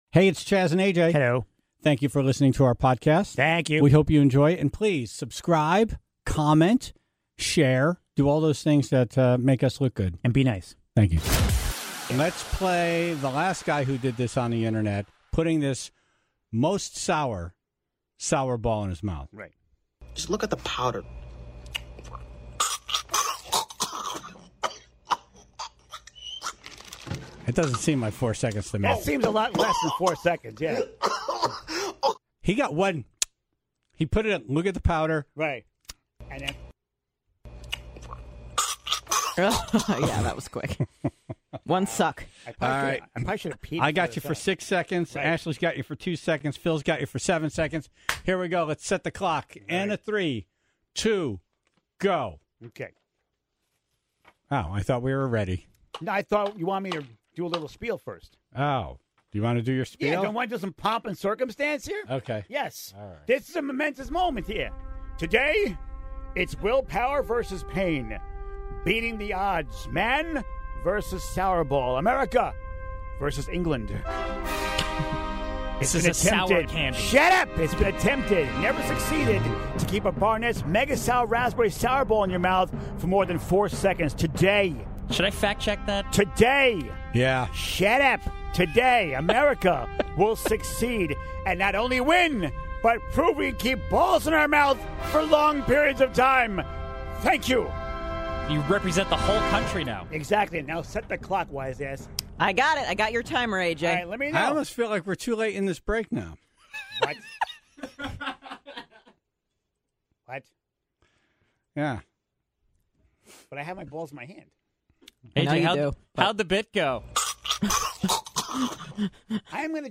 (27:55) Comedic improv genius Colin Mochrie was on the phone to talk about his show at the Ridgefield Playhouse, except he realized that he doesn't really know how to talk about it since everything will be happening live, in the moment.